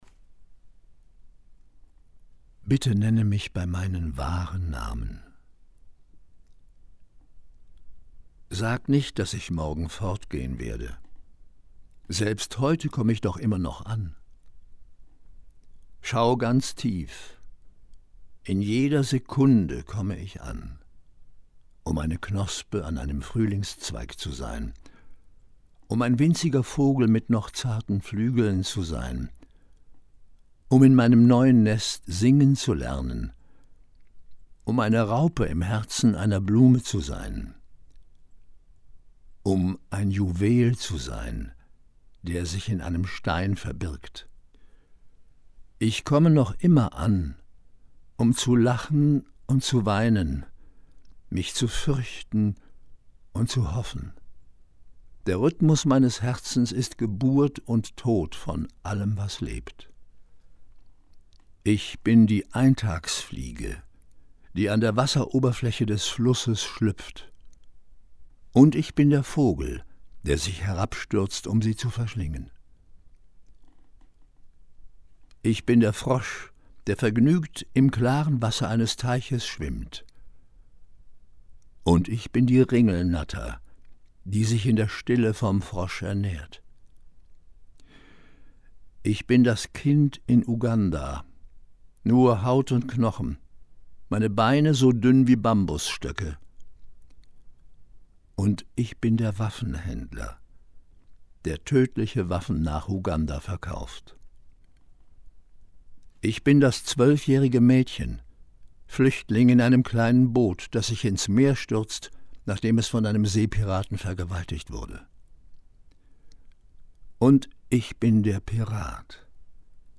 Hier vorgelesen: